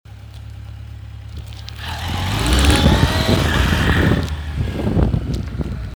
Klickern auf der rechten Seite am Zylinderkopf - Honda CB 1100 Forum
Hallo, habe seit einiger Zeit beim Beschleunigen auf der rechten Seite ein Geräusch als wenn ein Ventil klappert.
Ich häng noch eine kurze Datei vom stärkeren Beschleunigen an.